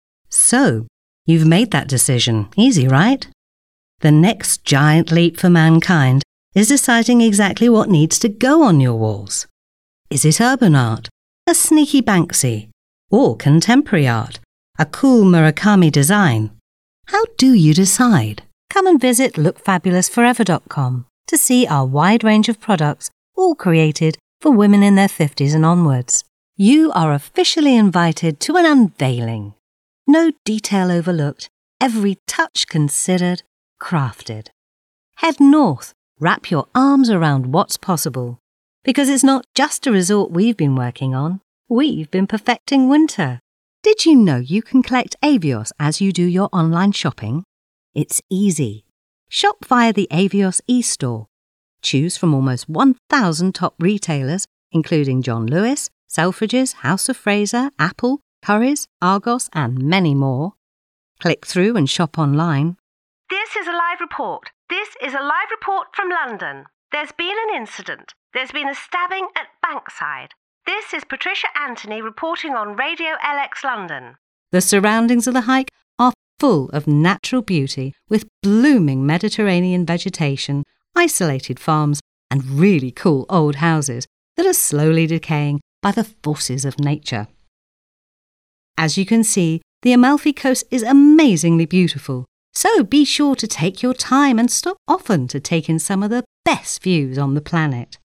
Female
English (British)
Adult (30-50), Older Sound (50+)
Sophisticated, conversational, natural, chatty, warm, friendly, passionate, persuasive. Slightly smokey quality.
Adverts Demo
Radio Commercials
0326adverts_demos.mp3